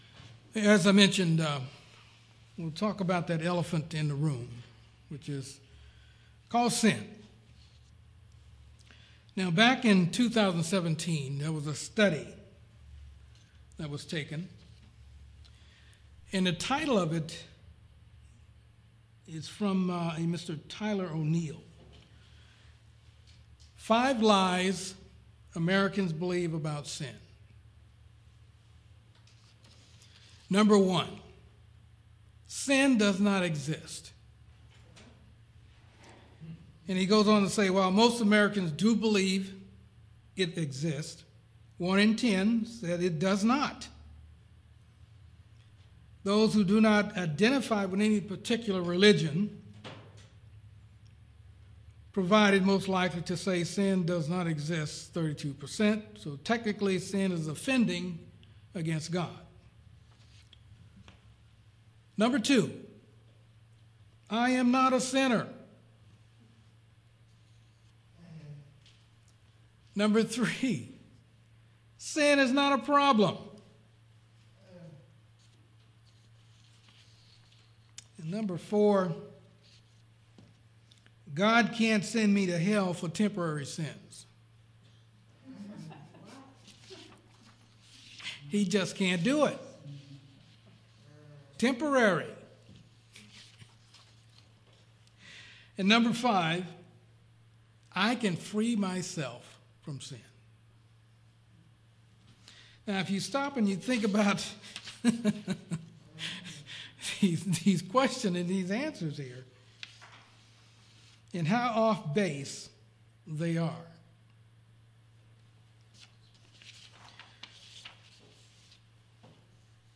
Sermons
Given in Yuma, AZ